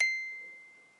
八音盒单音 " 09 d7
Tag: 单音符 音乐盒 间距-D7